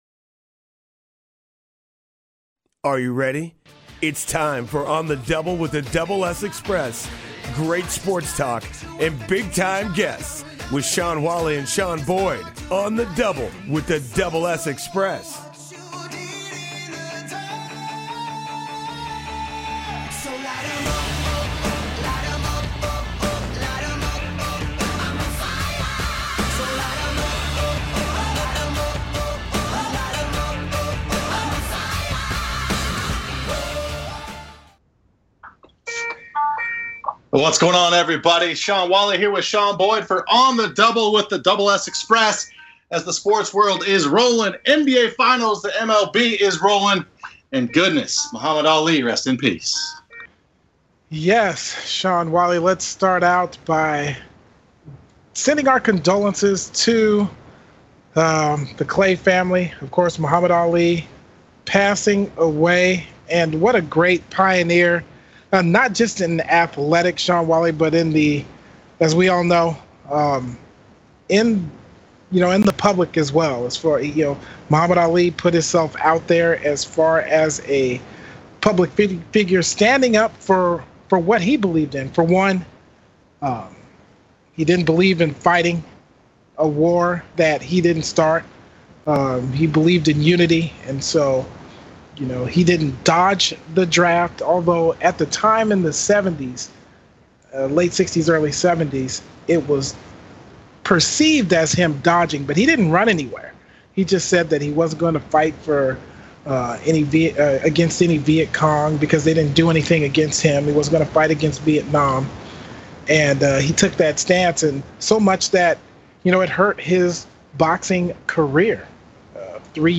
Talk Show Episode
sports talk show